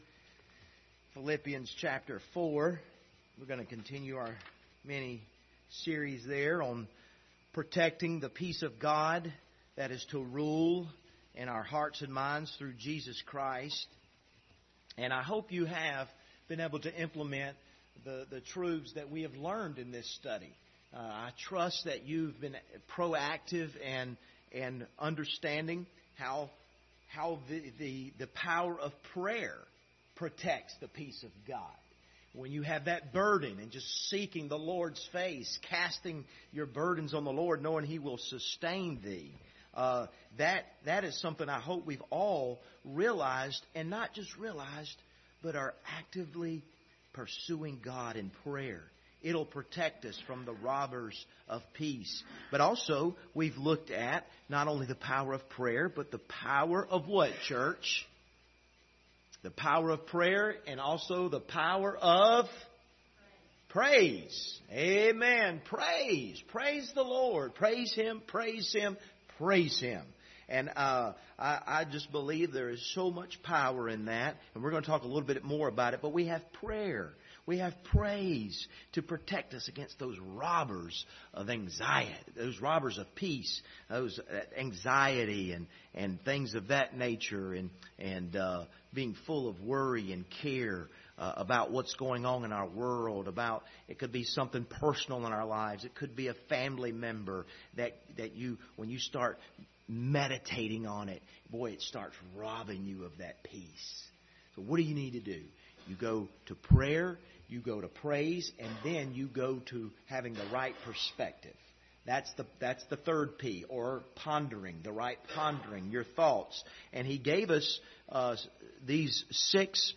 Passage: Philippians 4:8 Service Type: Wednesday Evening